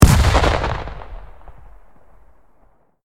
medium-explosion-7.ogg